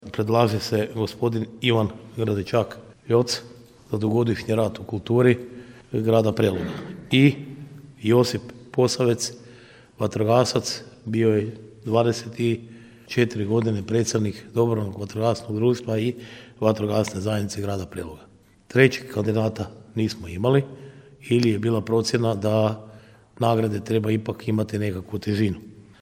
Na sjednici Gradskog vijeća prva točka dnevnog reda bilo je donošenje odluke o dodjelih javnih priznanja Grada za 2021. godinu.
Prijedloge koje je dalo Povjerenstvo za dodjelu javnih priznanja obrazložio je gradonačelnik Ljubomir Kolarek, a prenosimo iz našeg informativnog programa.